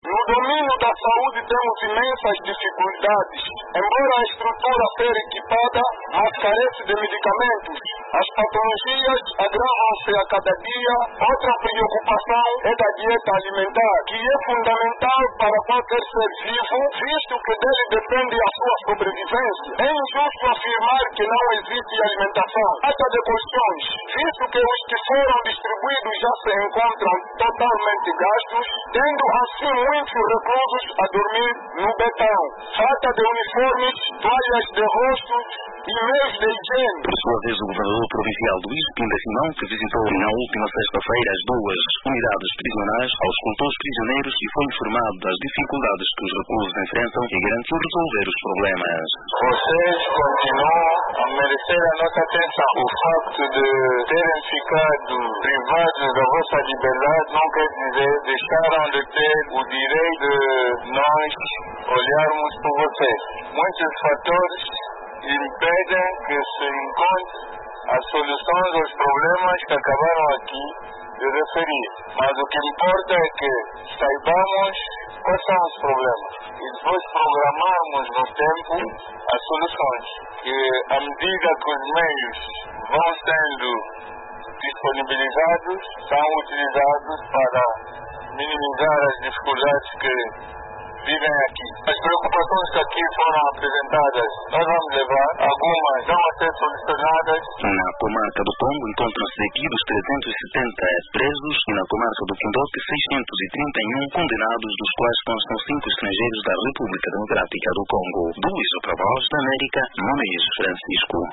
“Em termos de saúde temos dificuldades, falta de medicamento, as patologias agravam-se cada vez mais, os colchões aqui existentes já se encontram degradados, alimentação não condigna para a saúde de um ser humano” disse um dos reclusos numa mensagem dirigida ao governador que visitou as cadeias recentemente.
“Vocês continuam a merecer a nossa atenção, o facto de estarem privados da liberdade não vos tira o direito de nos olharmos por vocês, ouvimos as vossas preocupações e serão resolvidas”, disse o governador.